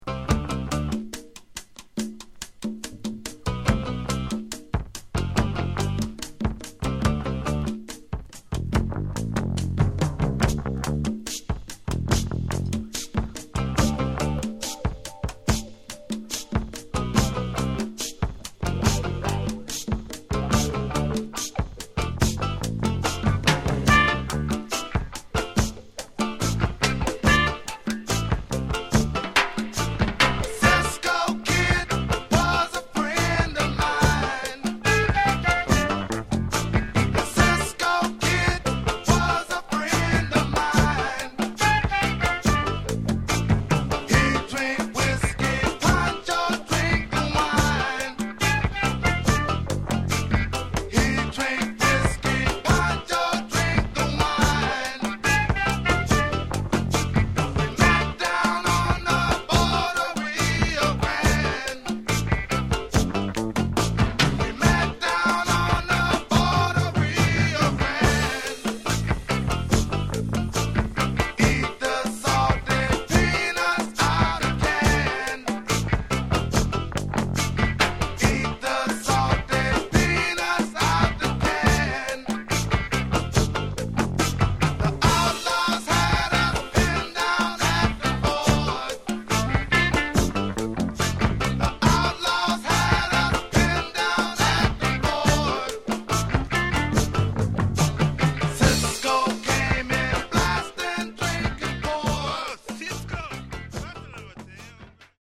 Genre: Funk/Hip-Hop/Go-Go
A unique, mesmerizing blend of Latin and Funk.